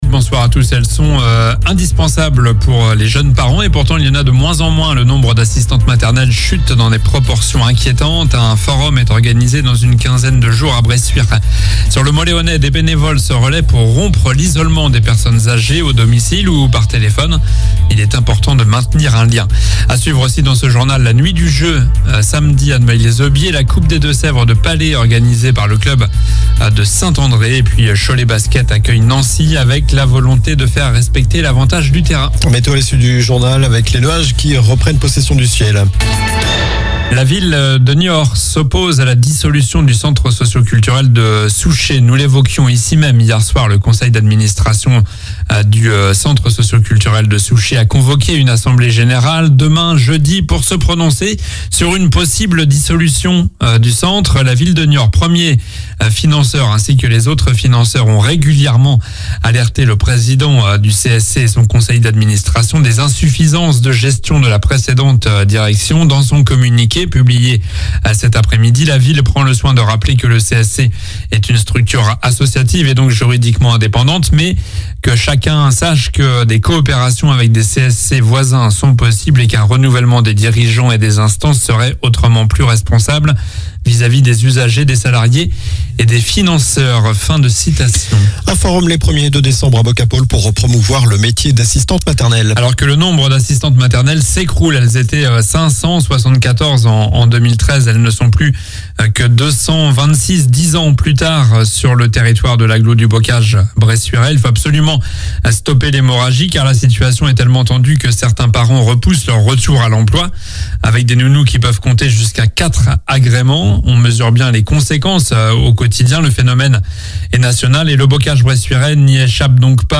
Journal du mercredi 15 novembre (soir)